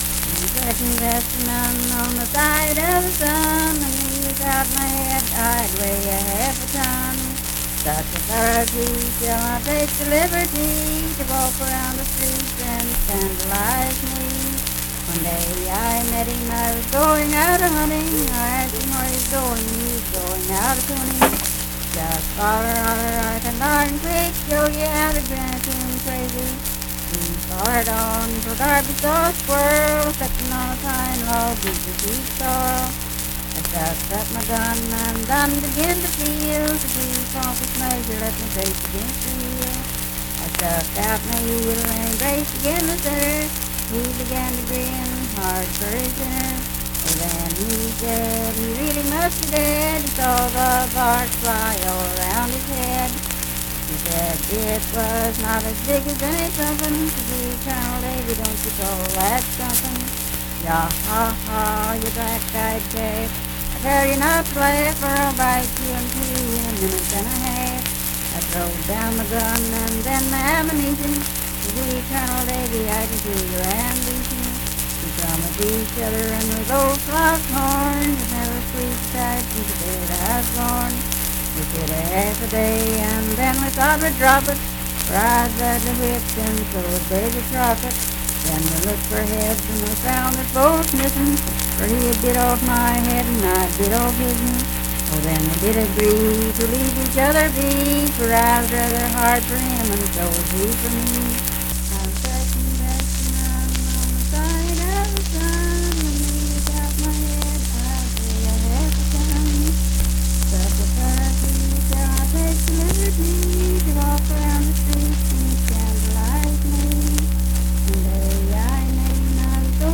Unaccompanied vocal music
Performed in Daybrook, Monongalia County, WV.
Voice (sung)